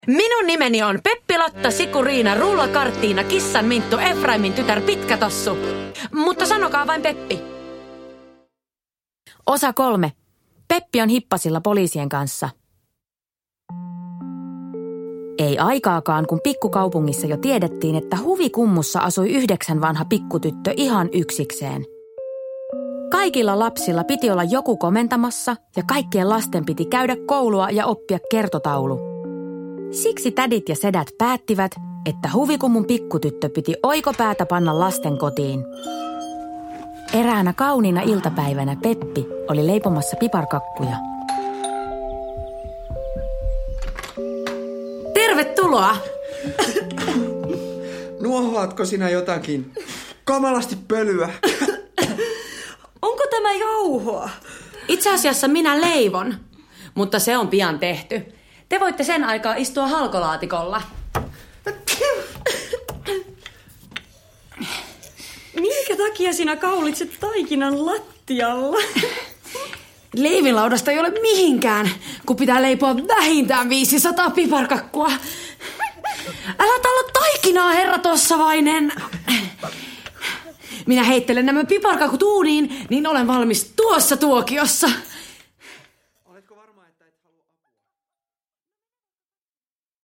Kaikki on vinksin vonksin näissä iloisissa kuunnelmissa!
Kaikille tuttu Peppi Pitkätossu ilahduttaa nyt eläväisinä ja hauskoina kuunnelmina.